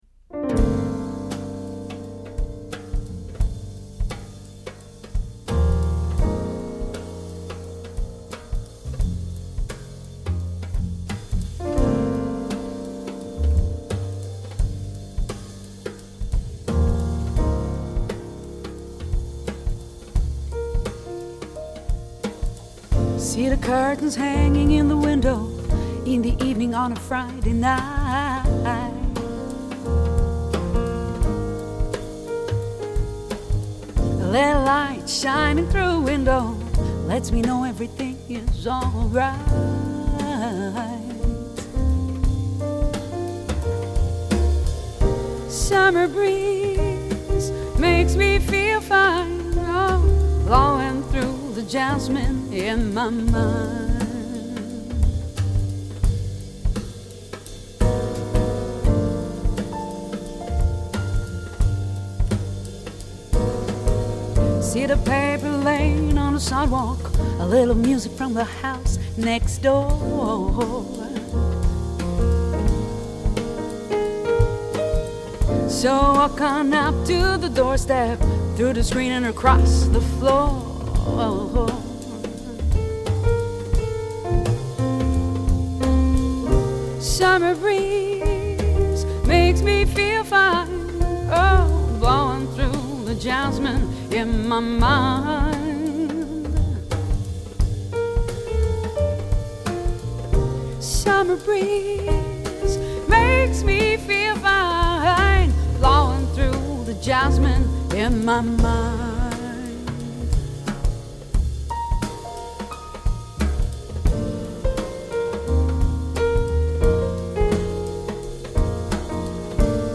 ★ 經典爵士曲風，優雅靈氣展現四季時序變遷氛圍！
★ 天王級錄音大師親自操刀，類比直刻展現完美音效！
Summer